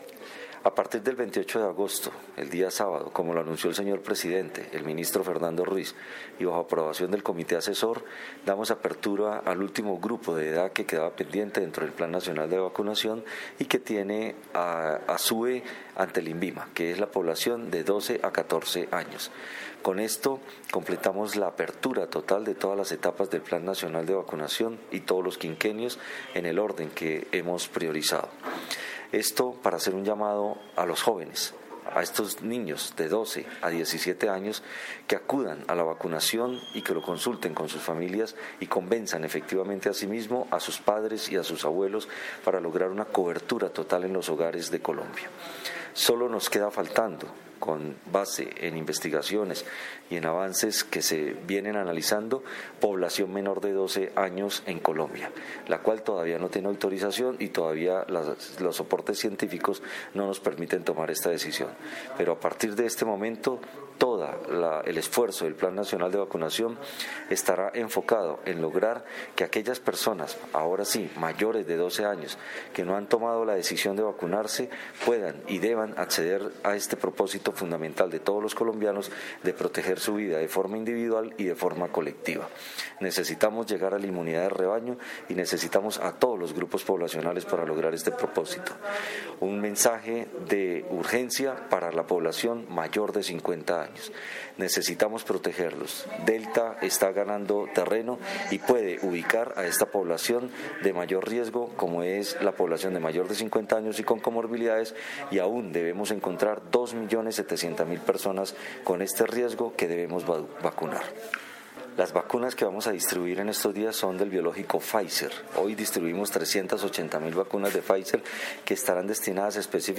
Audio de Gerson Bermont, director de Promoción y Prevención.